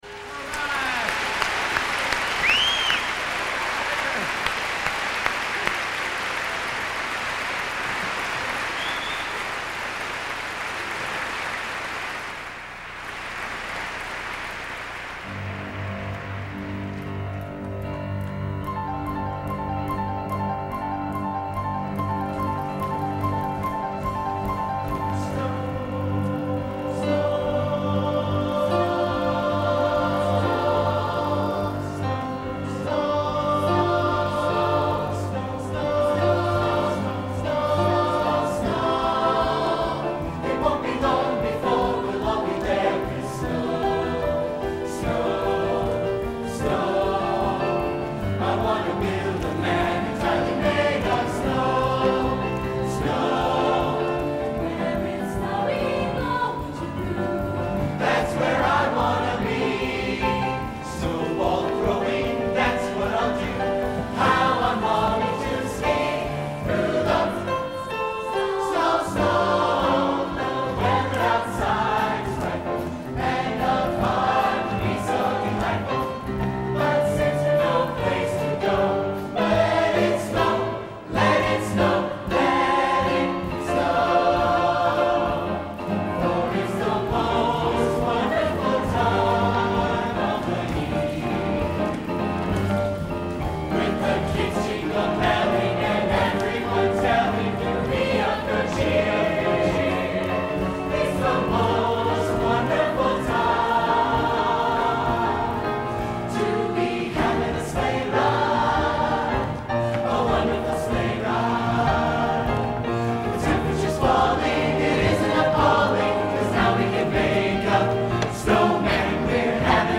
Collection: Christmas Show 1994
Location: West Lafayette, Indiana
Genre: | Type: Christmas Show |